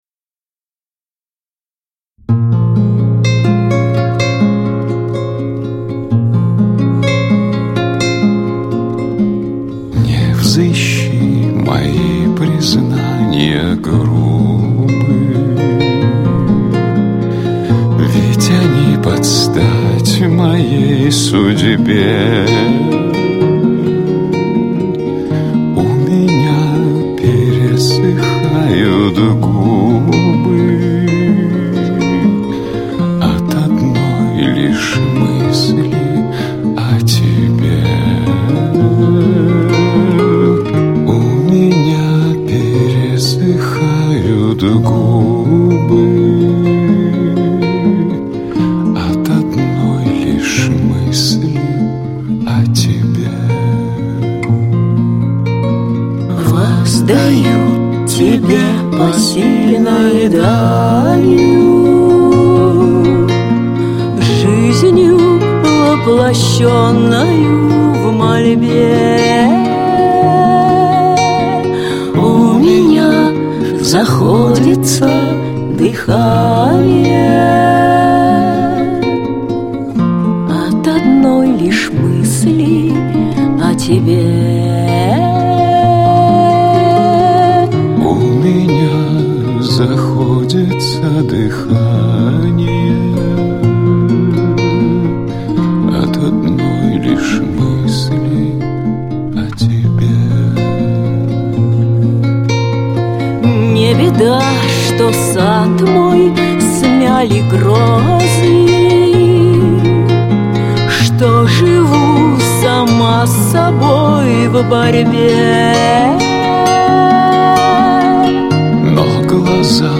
красивый романс